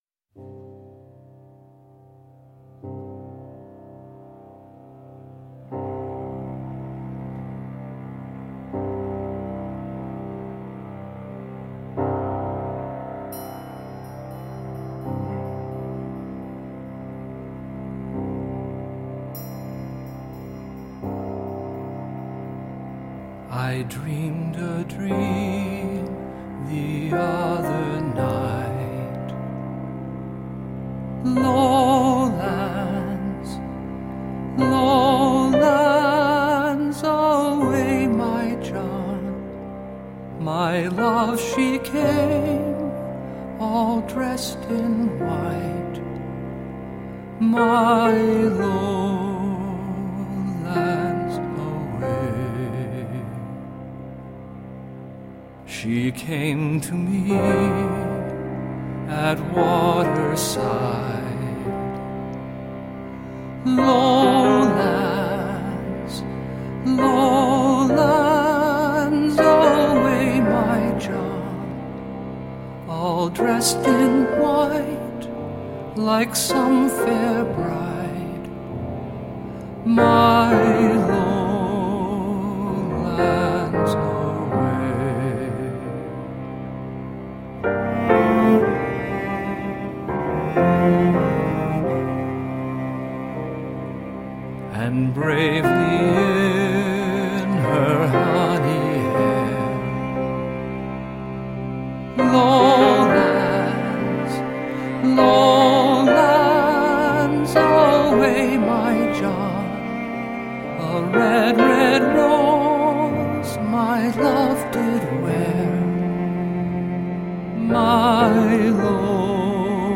New age meets indian cello.
Tagged as: World, Folk, World Influenced